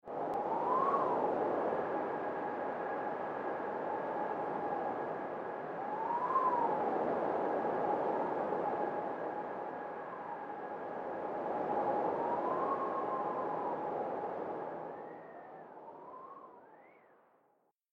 دانلود آهنگ باد 12 از افکت صوتی طبیعت و محیط
دانلود صدای باد 12 از ساعد نیوز با لینک مستقیم و کیفیت بالا
جلوه های صوتی